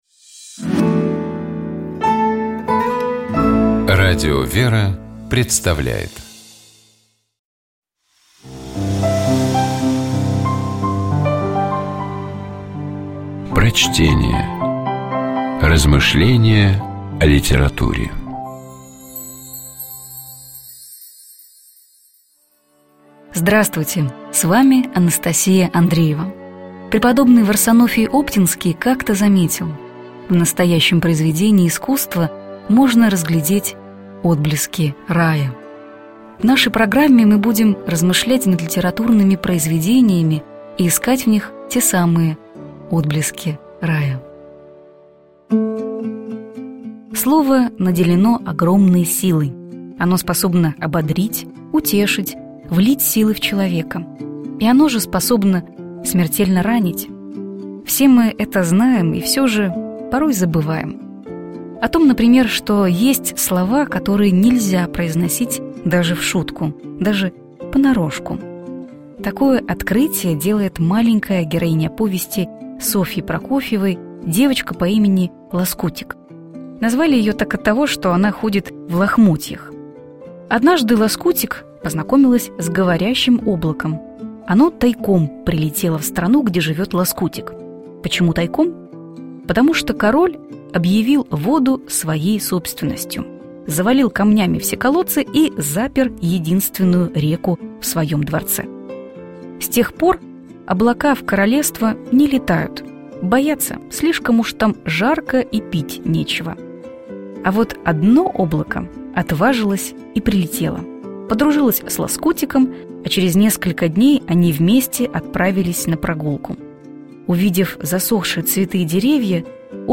Prochtenie-S_-Prokofeva-Loskutik-i-oblako-Chego-nelzja-govorit-dazhe-v-shutku.mp3